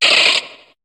Cri de Polichombr dans Pokémon HOME.